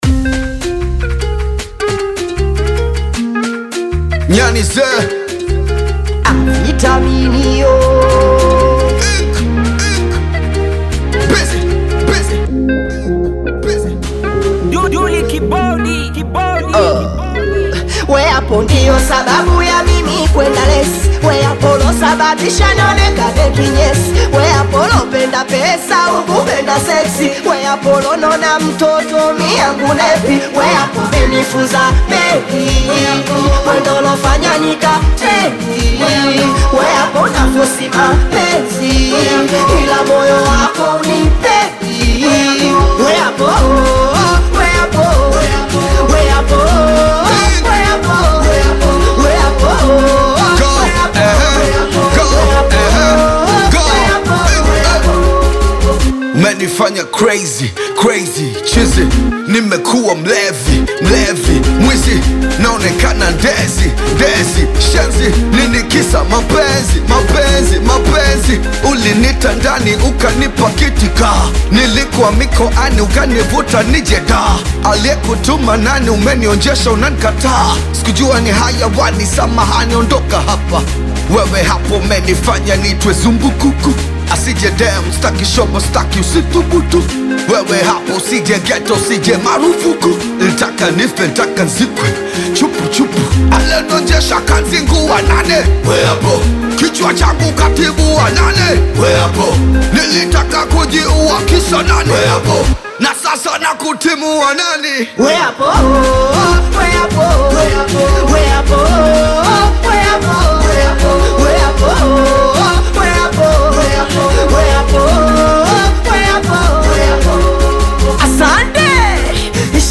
Singeli Mpya